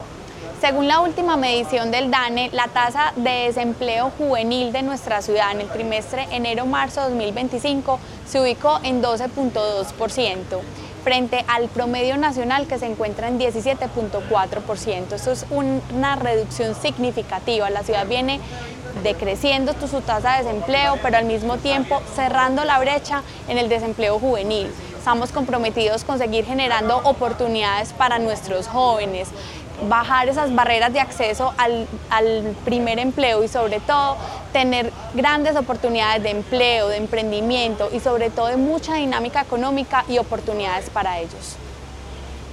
Declaraciones de la secretaria de Desarrollo Económico, María Fernanda Galeano